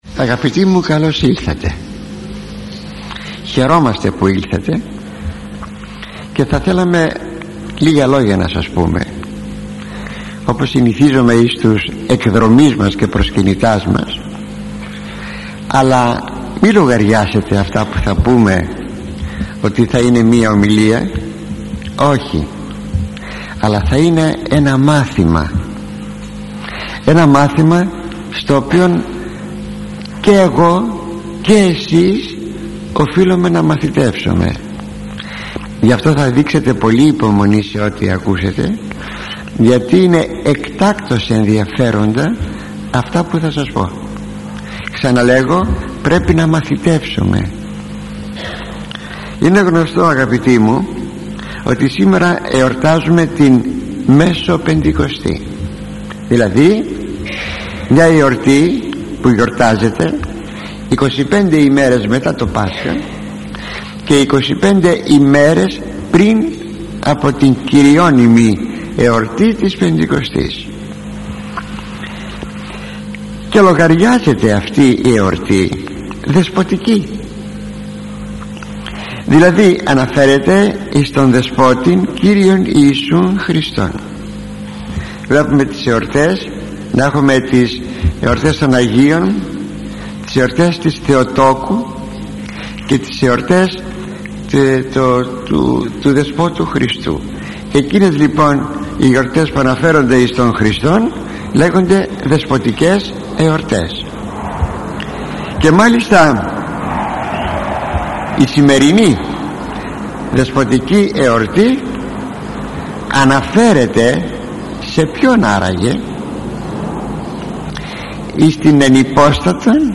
Η εορτή της Μεσοπεντηκοστής – ηχογραφημένη ομιλία